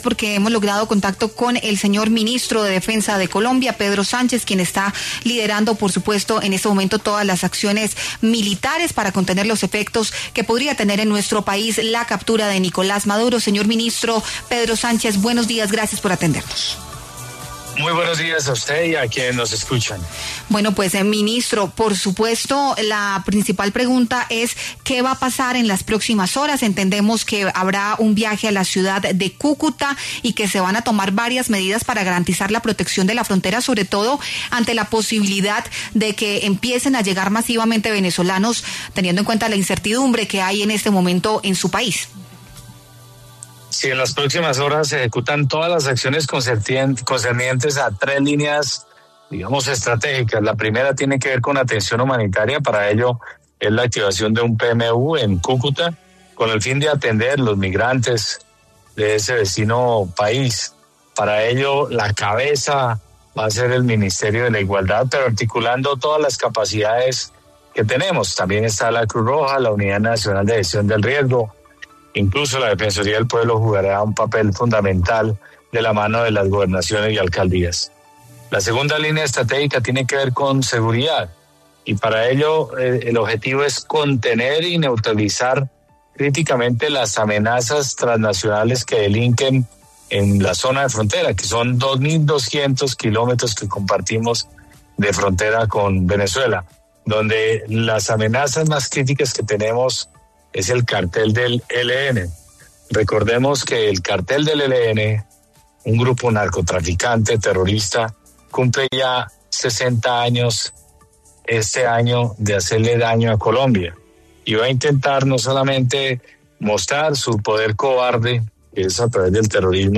Pedro Sánchez, ministro de Defensa, habló en La W sobre las acciones adoptadas para garantizar la seguridad en la frontera tras la captura del presidente Nicolás Maduro.